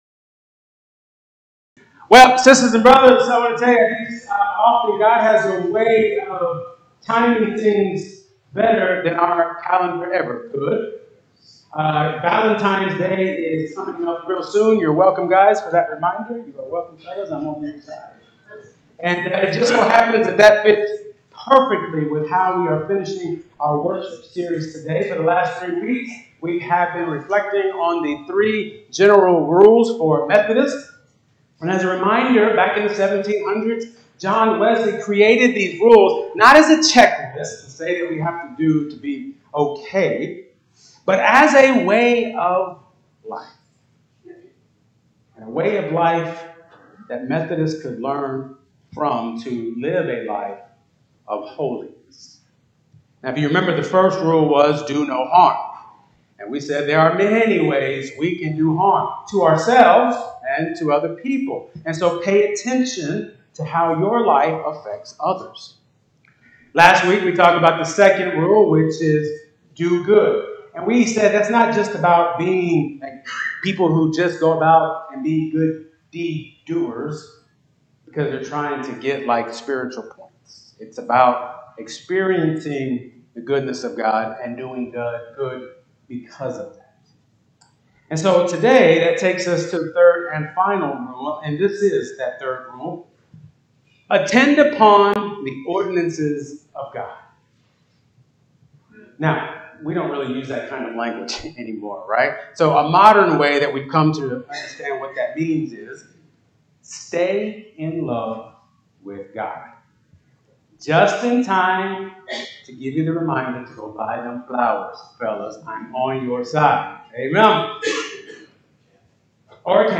++Apologies for the bad audio and video quality on this video++